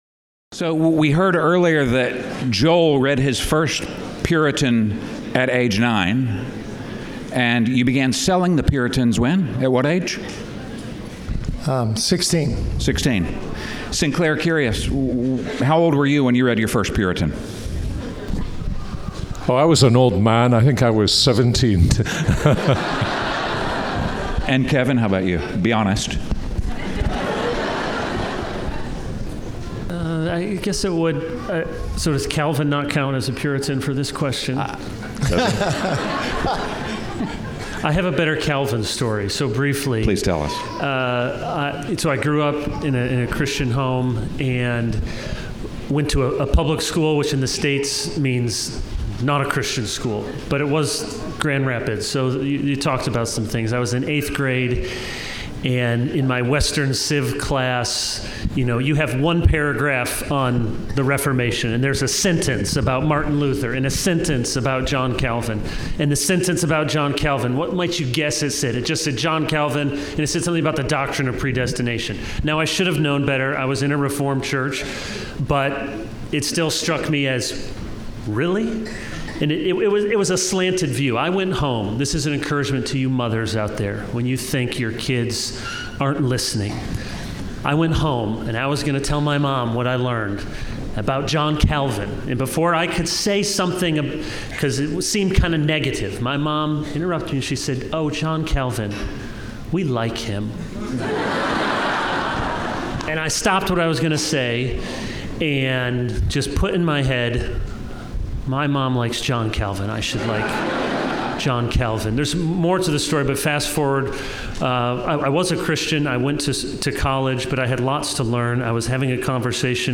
Puritan Conference | Dubai